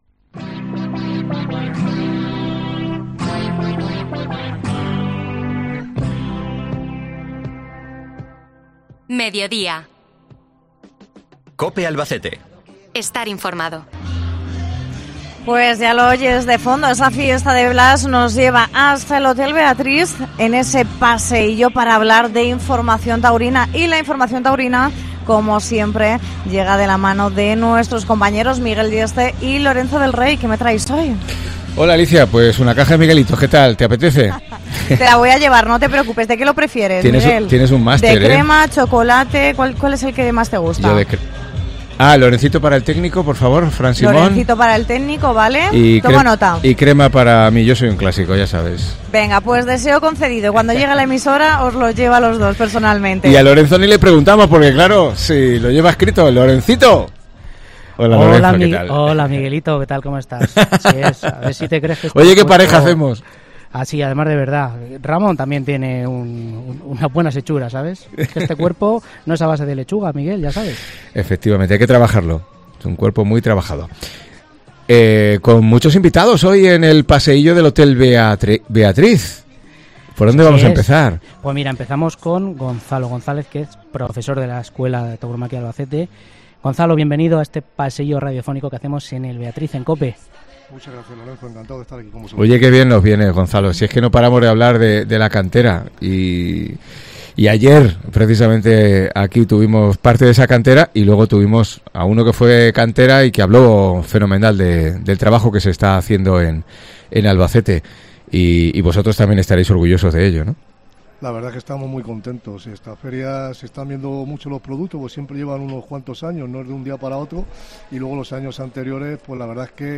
Paseíllo taurino 13 de septiembre desde el Hotel Beatriz